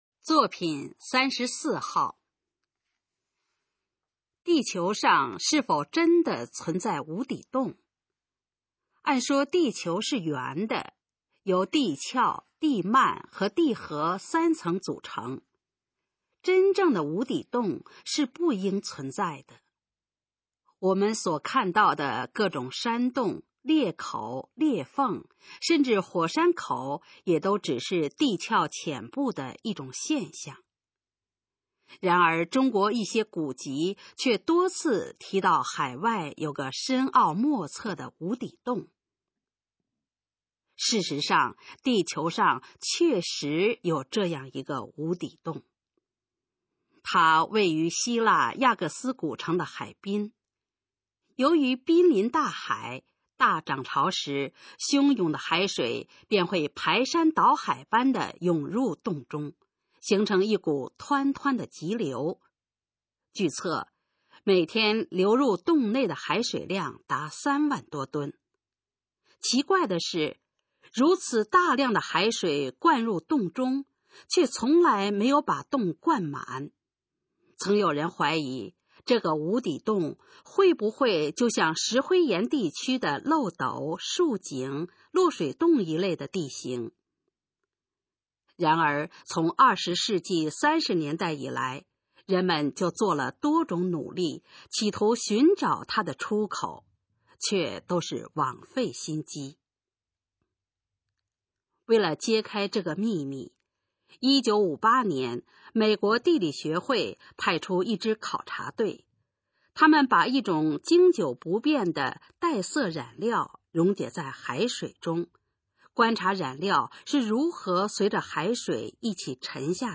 首页 视听 学说普通话 作品朗读（新大纲）
《神秘的“无底洞”》示范朗读_水平测试（等级考试）用60篇朗读作品范读